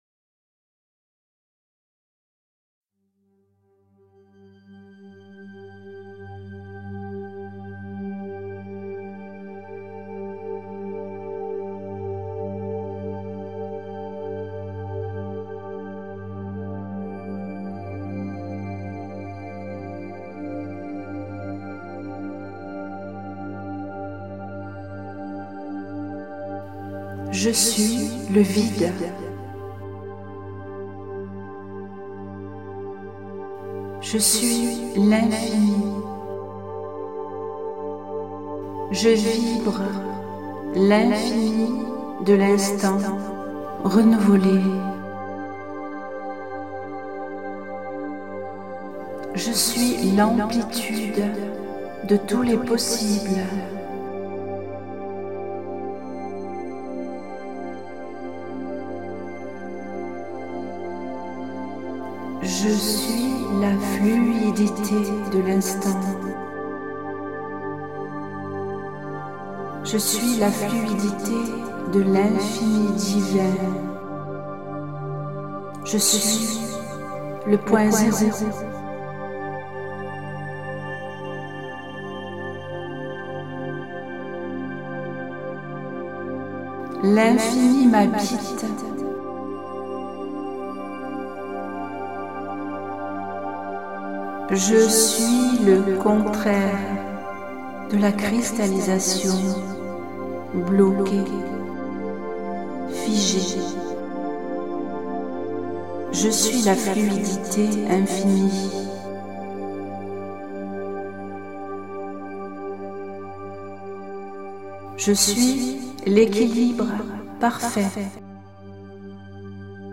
Cliquez sur le lien ci dessous “Point Zéro” pour écouter la méditation audio.